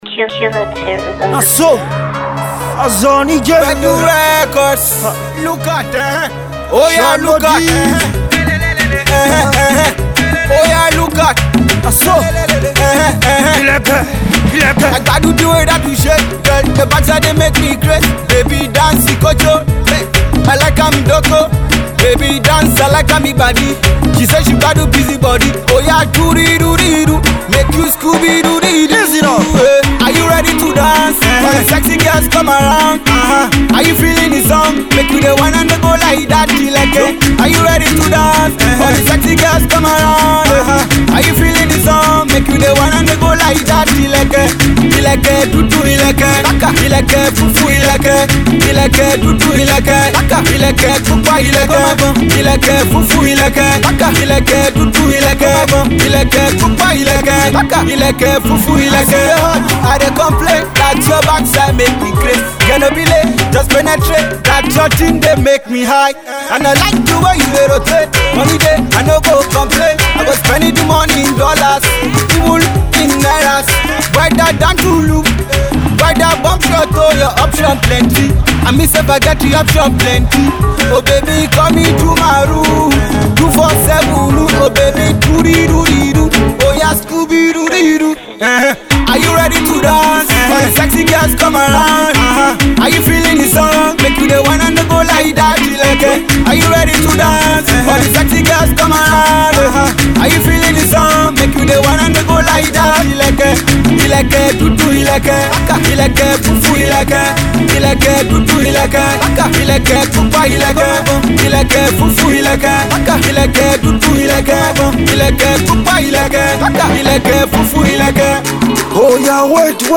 Pop/Party Track